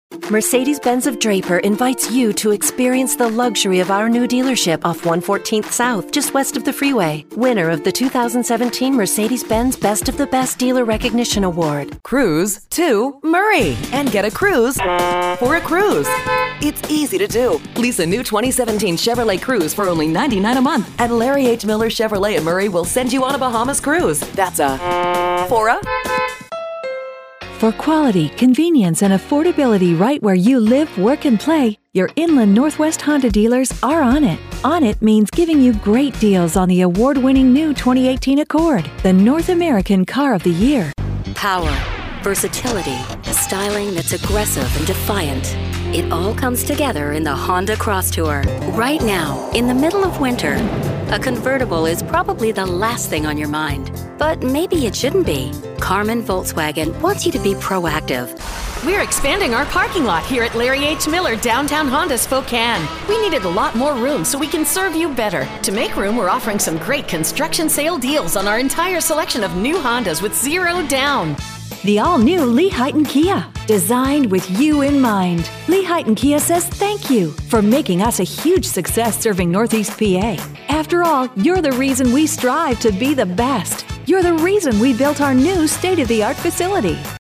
Automotive Commercials – Female Voices
AutomotiveFemaleDemo.mp3